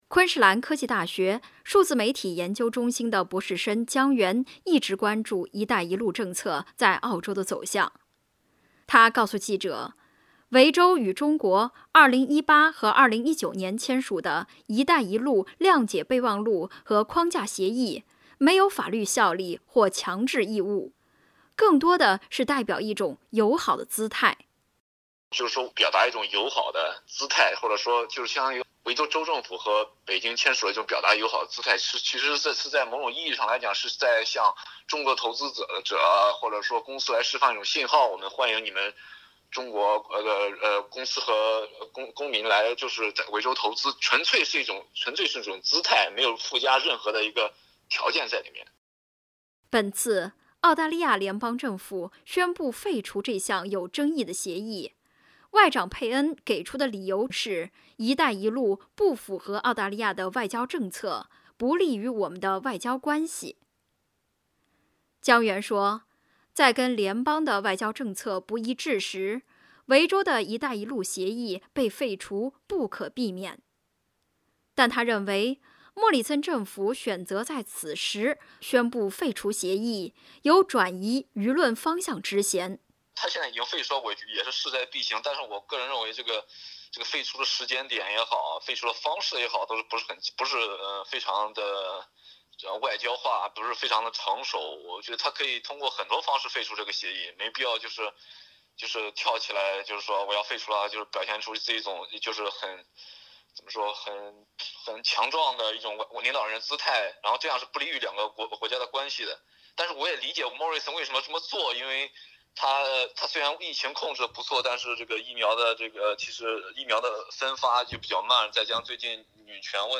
（請聽采訪，本節目為嘉賓觀點，僅供參考，不代表本臺立場） READ MORE 你好，澳大利亚 澳大利亞人必鬚與他人保持至少 1.5 米的社交距離，請查看您所在州或領地的最新社交限制措施。